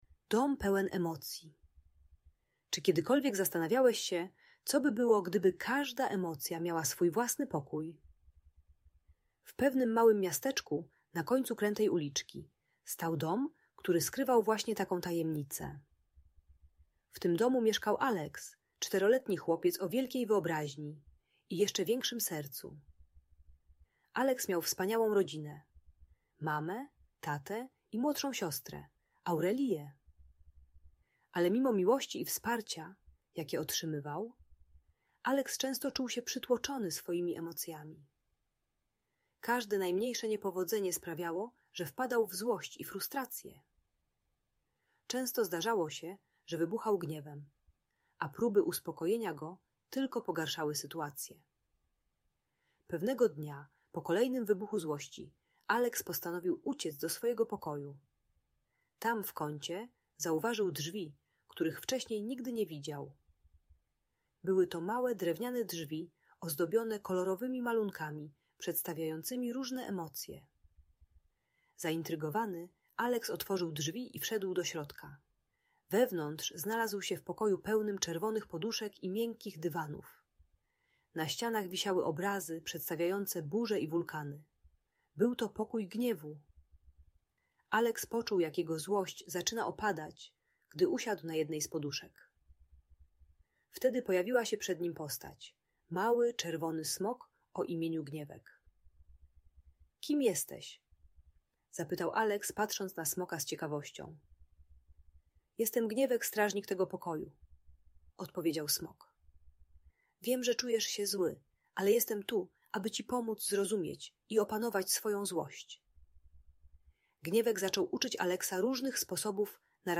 Dom Pełen Emocji - Bunt i wybuchy złości | Audiobajka